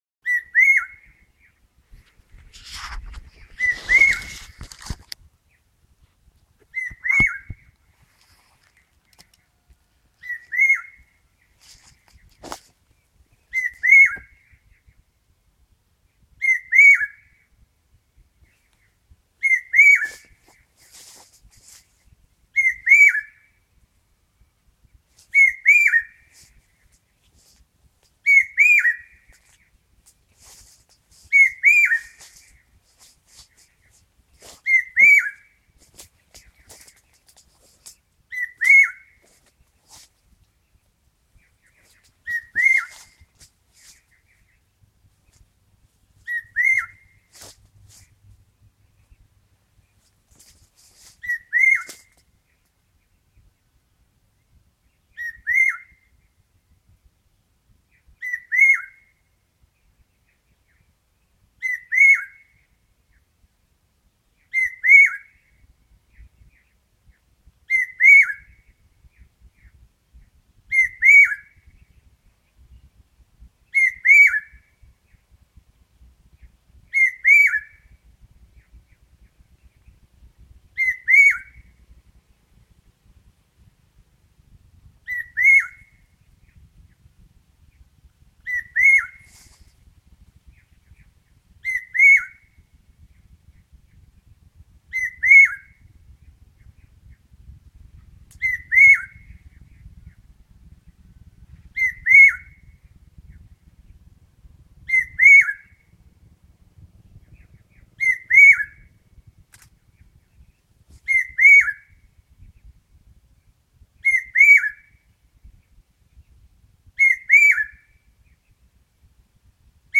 他总是落在大树的顶端，叫声能听三公里，因为我在拍的时候，他和远山的同种鸟在对话。
这鸟的叫声似乎没有超过三个转折，但声音很大速度及音调不断增高至狂暴高潮，间隔一分多钟后继续再叫。
在拍这只鸟时，因为叫声很大，我就用手机录了1分多钟叫声，我想把他放上，目前还不会做，我正在摸索。
还有一段录音，在22楼，是用手机录的这个鸟的叫声。
dujuan.mp3